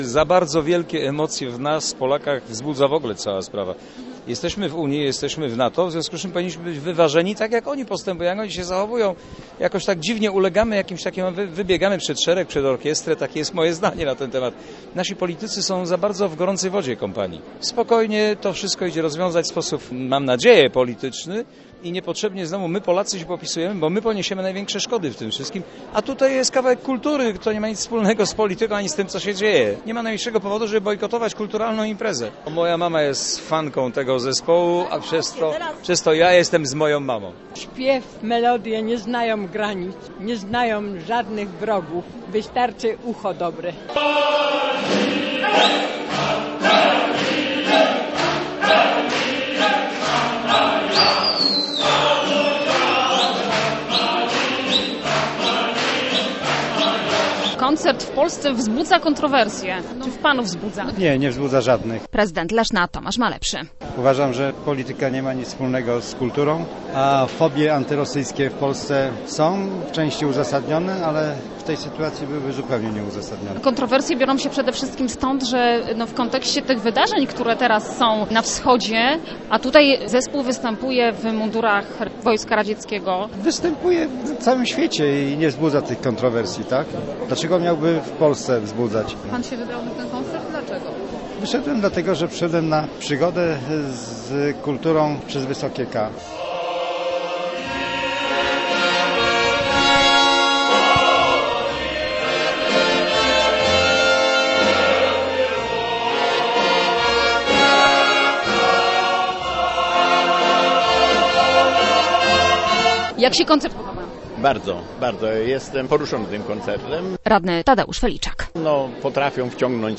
Chór liczy 60 osób.
chor.mp3